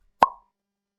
button-click.mp3